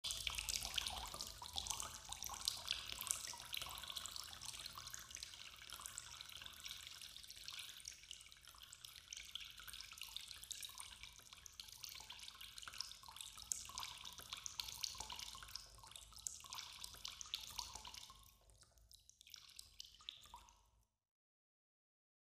the-sound-of-dripping-in-a-cave